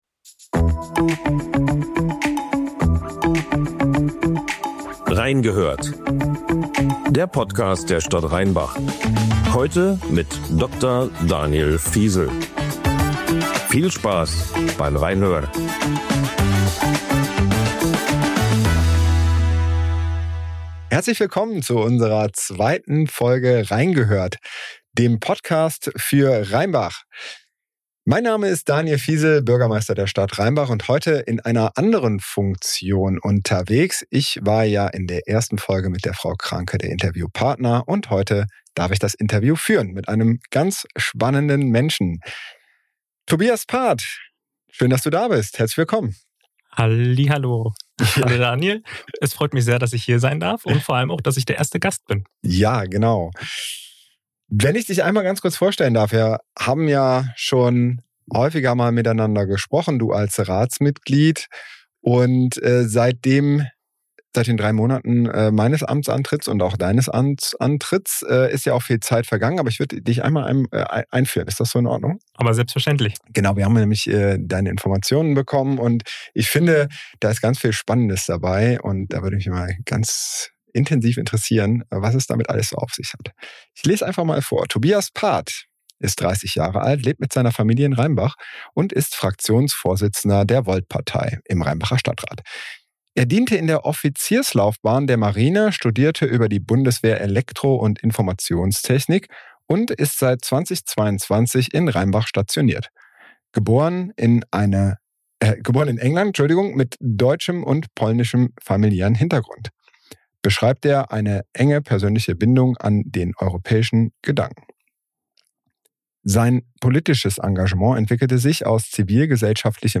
In der zweiten Folge von „Reingehört“, dem Podcast der Stadt Rheinbach, übernimmt Bürgermeister Dr. Daniel Phiesel die Rolle des Interviewers. Zu Gast ist Tobias Path, Fraktionsvorsitzender der Volt-Partei, im Rheinbacher Stadtrat.